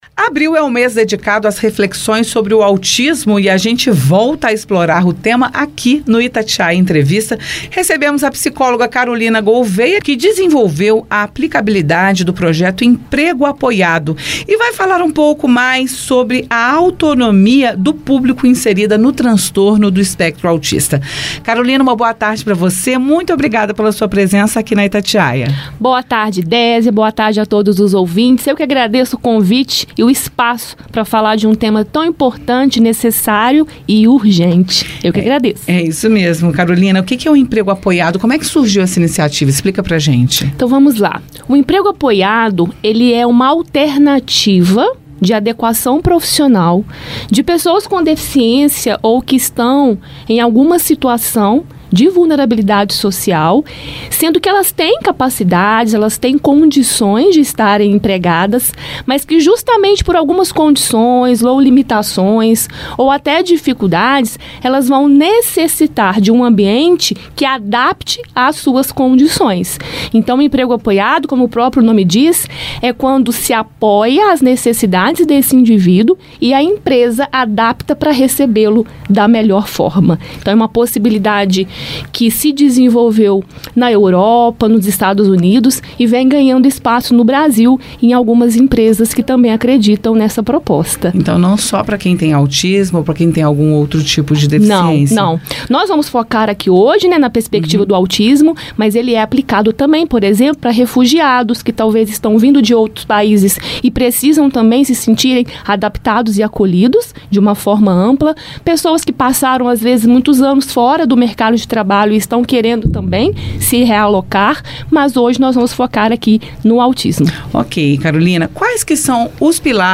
Ouça no Itatiaia Entrevista.